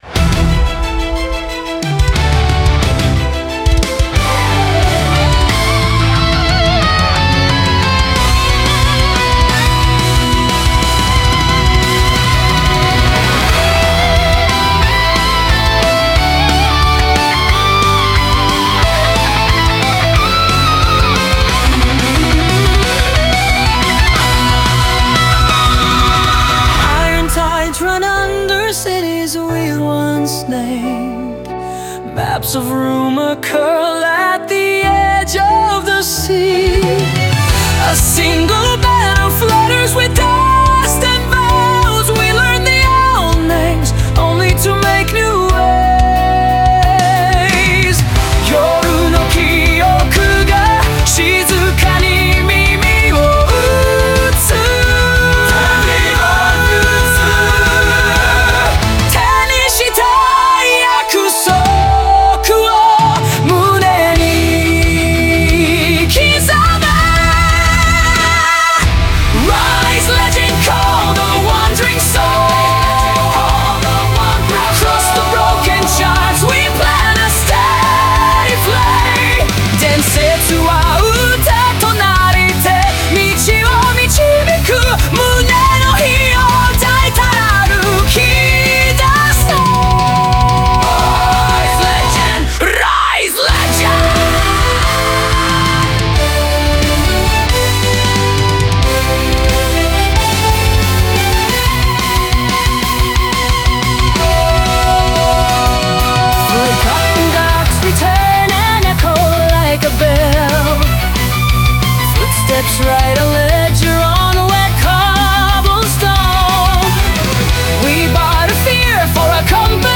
Melodic Power Metal
Target tempo 230 BPM.